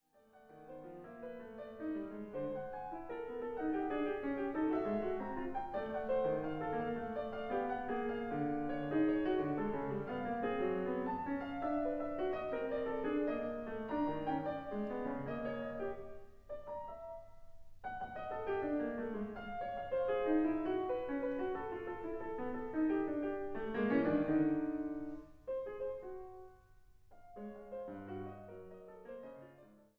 アコースティック
アバンギャルド
インストゥルメンタル
録音・編集も、ライブの緊迫した臨場感を伝えつつ、クオリティの高い仕上がり。
ピアノソロ